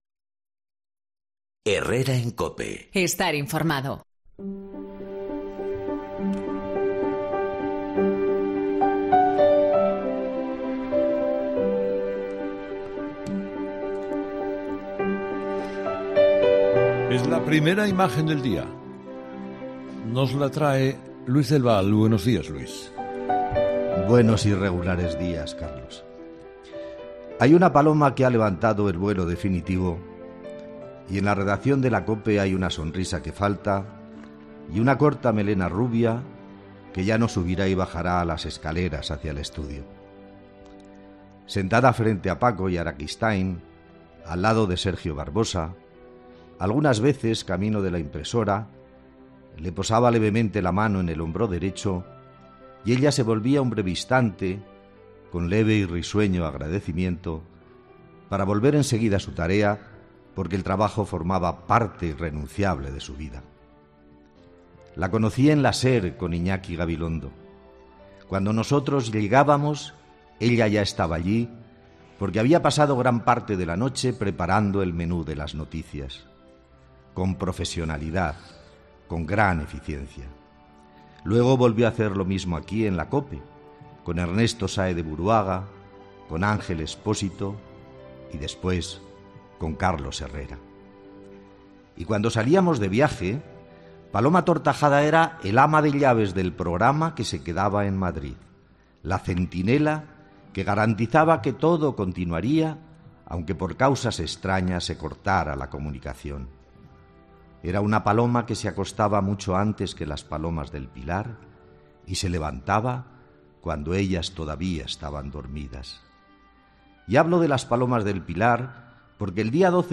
El sentido recuerdo de Luis del Val a Paloma Tortajada en 'Herrera en COPE'